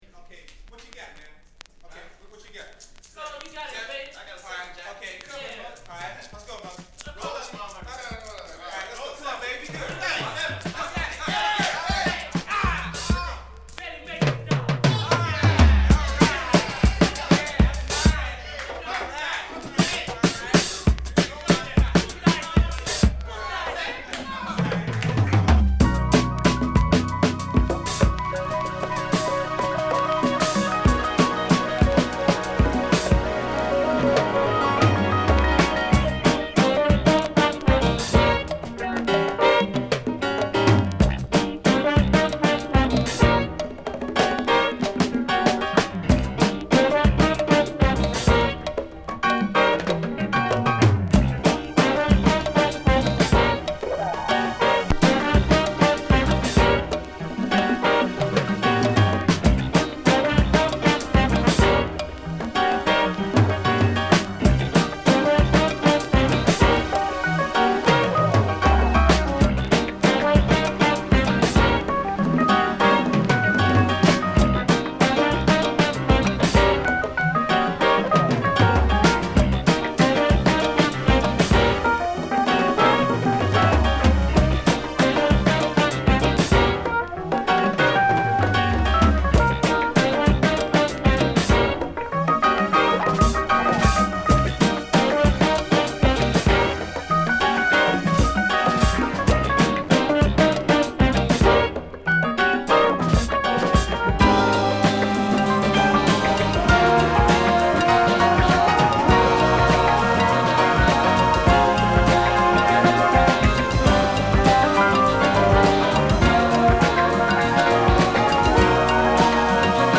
キラーなジャズファンクを満載！
＊スレ多いです。